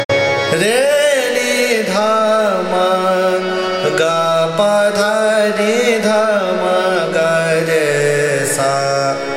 Raga
ThaatBhairavi
Avarohar’ n d m g P d n d M g r S
Bilaskhani Todi (Avaroha)